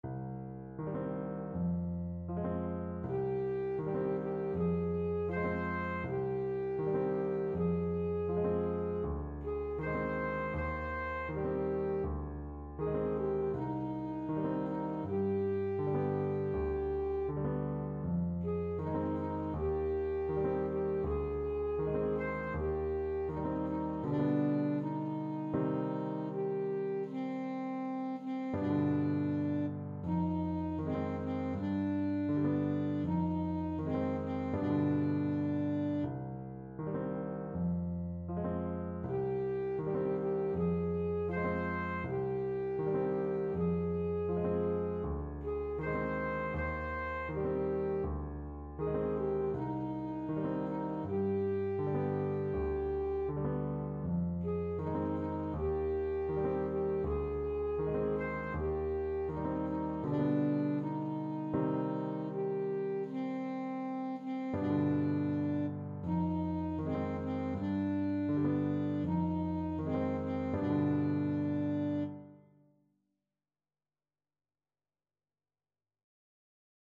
Alto Saxophone version
Alto Saxophone
4/4 (View more 4/4 Music)
Andante
world (View more world Saxophone Music)